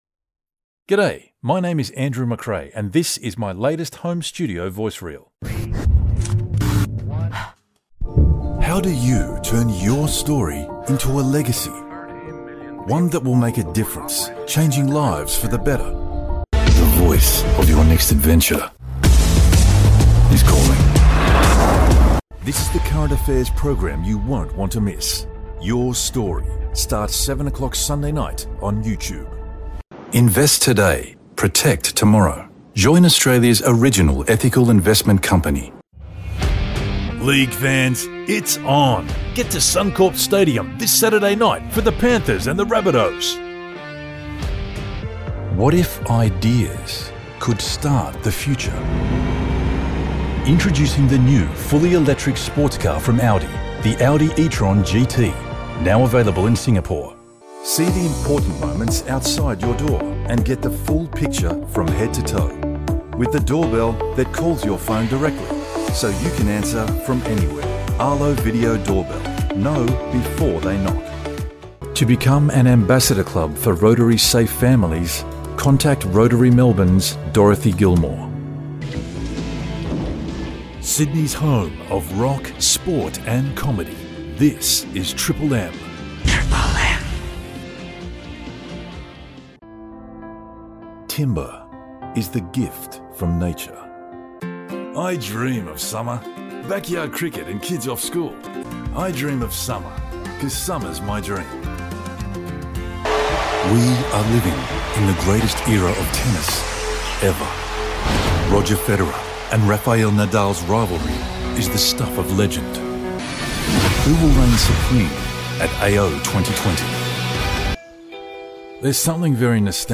2025 Home Studio Demo
English - Australian
20 year voice over veteran across all genres
The perfect mix of casual read, an air of authority, and a smile you can hear?